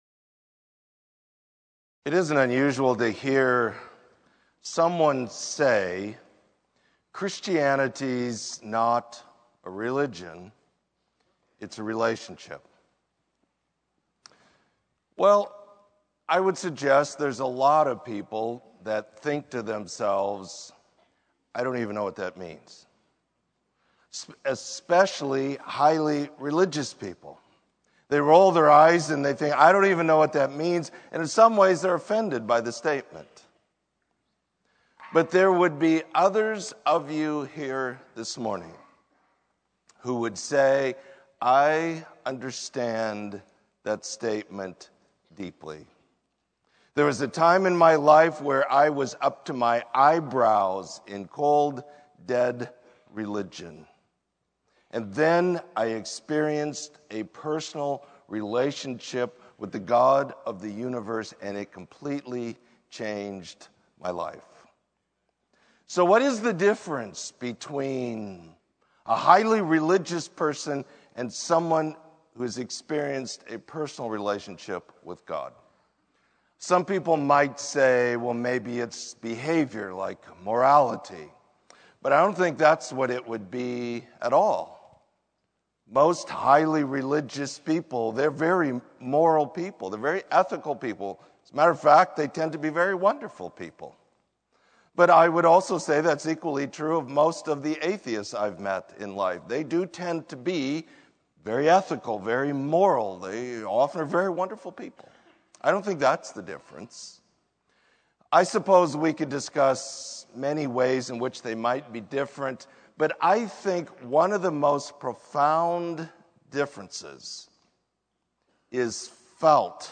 Sermon: My God In Whom I Trust